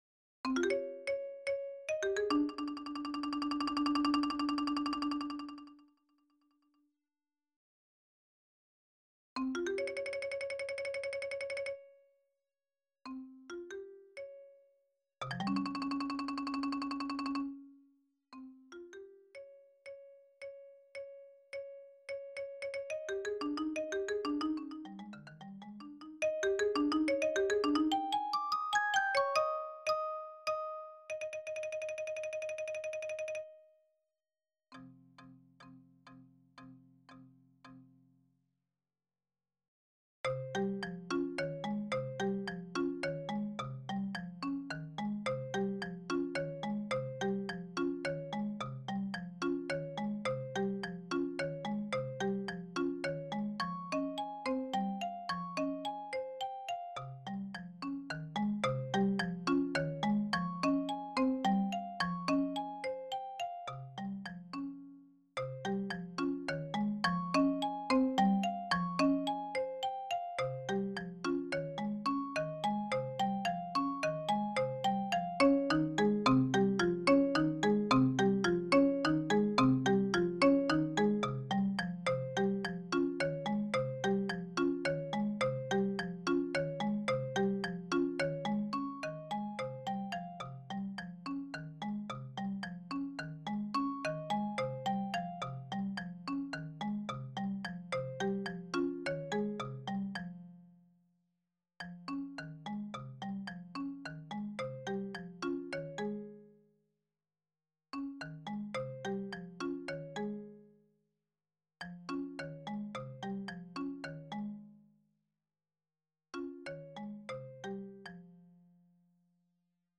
Percussion Pieces
(MIDI realization)
for solo marimba